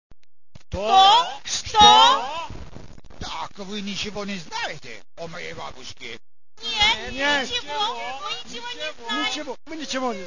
5 Giugno 1999, Cortile di Palazzo Rinuccini
Adattamento scenico in lingua originale del racconto di Puskin ad opera dei partecipanti al primo corso di teatro